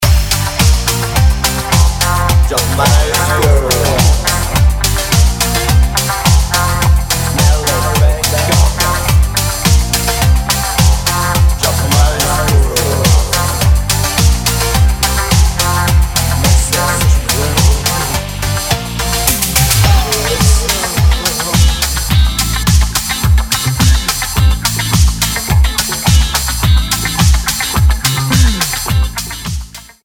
シンセ・ディスコ/エレクトロ度が格段に上昇、よくぞここまで違和感無く手を加えられたな、という仕上がり。